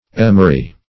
Emery \Em"er*y\, n. [F. ['e]meri, earlier ['e]meril, It.